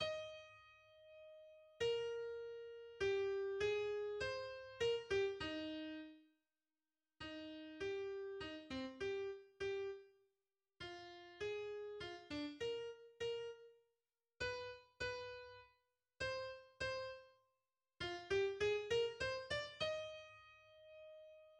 Effectif Trio de violon, violoncelle, et piano
Allegro
Il débute immédiatement avec un premier thème énergique, joué forte, déclamé par les trois instruments à l’unisson :